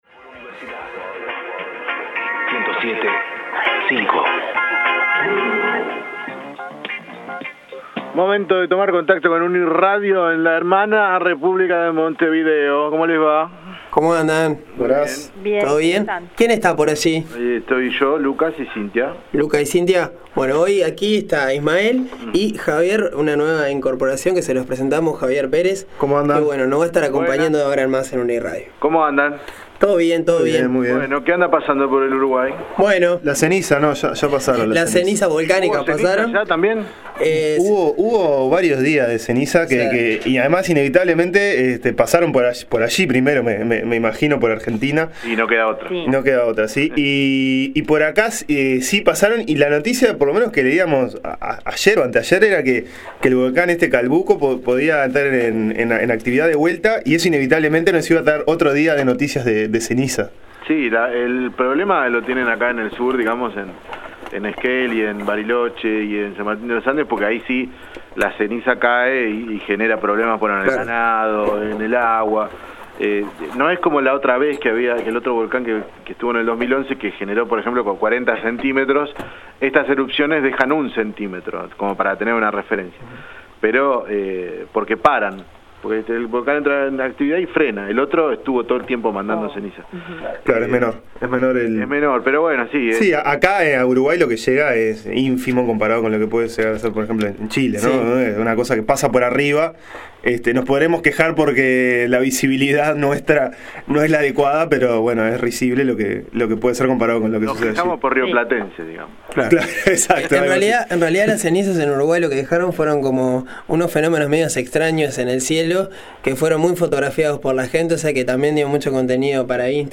En el dúplex de esta semana con los colegas de No Se Sabe de Radio Universidad Nacional de La Plata compartimos las noticias más destacadas en ambos márgenes del Río de la Plata.